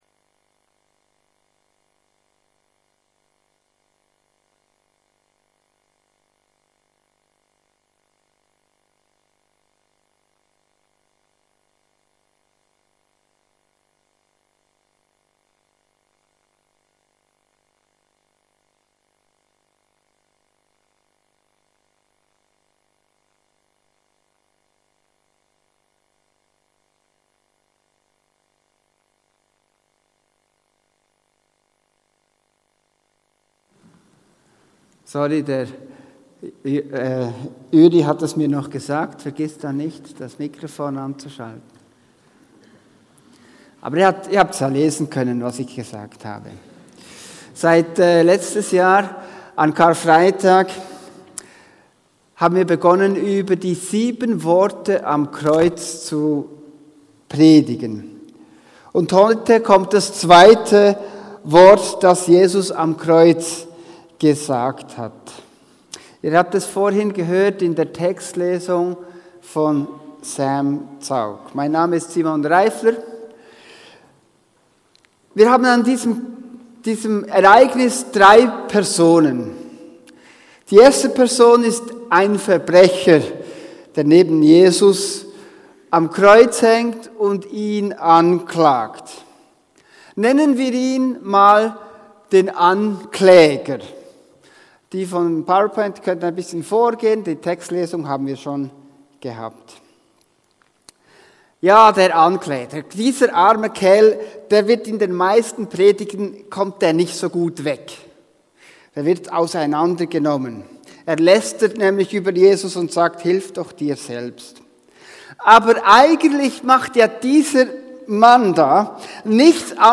Predigten, Interviews und Messages